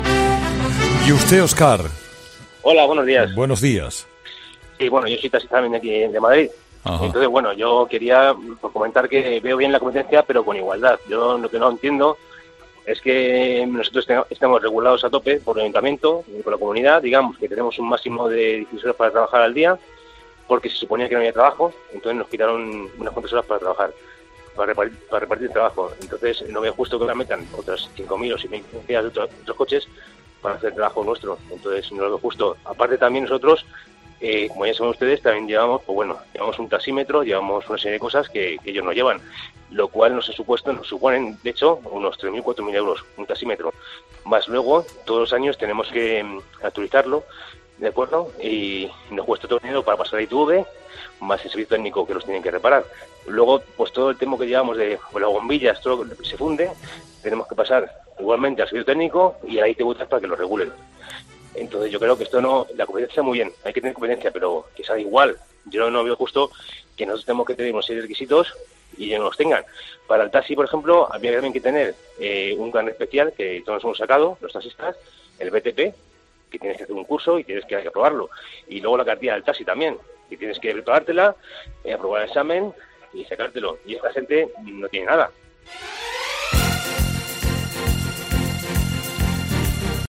taxista de profesión